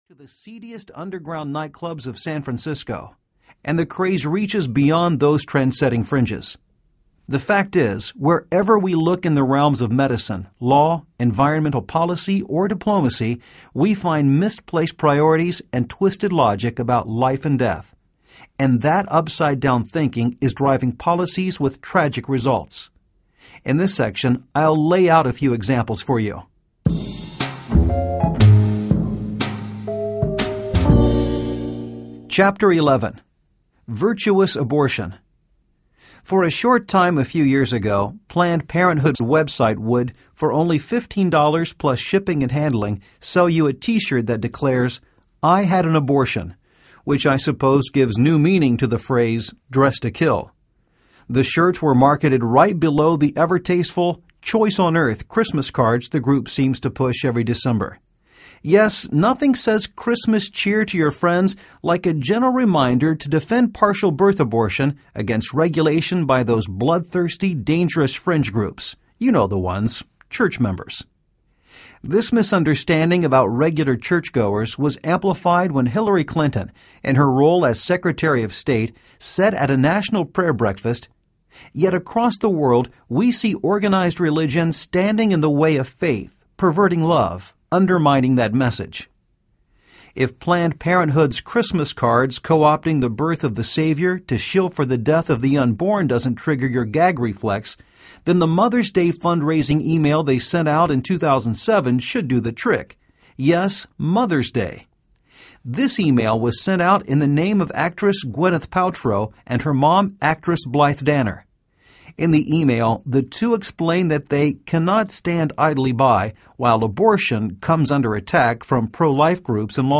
Living On Our Heads Audiobook
Narrator